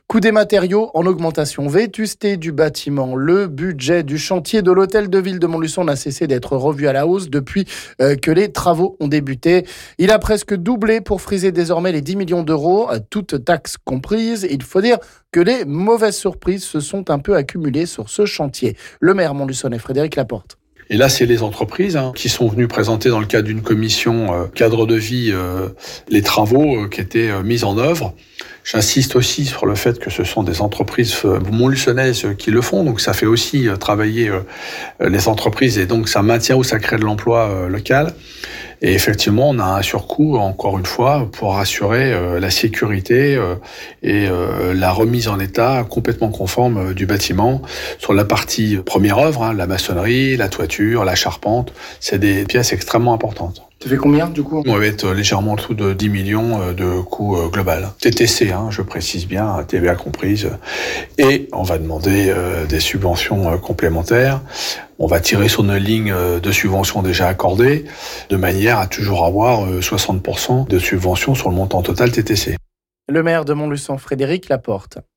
C'est ce que nous dit le maire de Montluçon Frédéric Laporte...